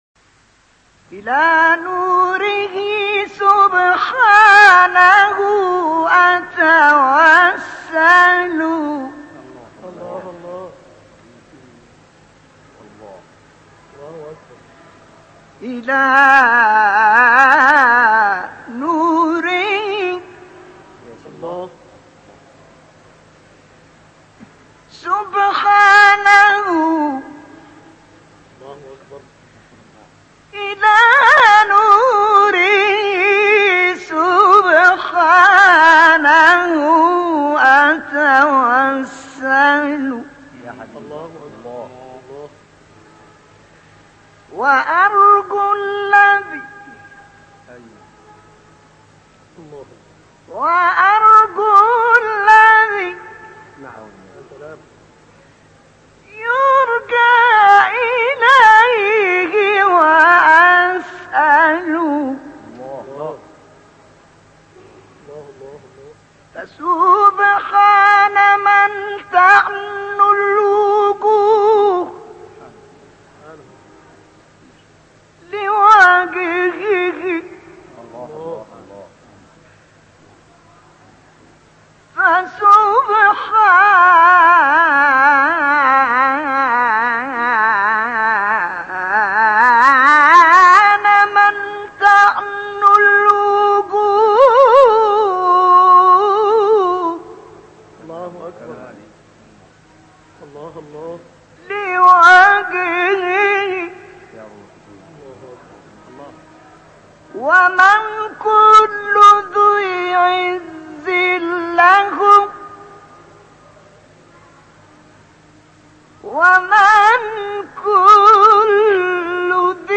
به گزارش خبرگزاری بین المللی قرآن(ایکنا) ابتهال معروف و ماندگار «میلاد طه» اثری از طه الفشنی، قاری و مبتهل برجسته مصری در کانال تلگرامی اکبر القراء منتشر شده است. این نسخه تواشیح بصورت کامل به مدت نیم ساعت، به مناسبت میلاد پیامبر گرامی(ص) منتشر شده است.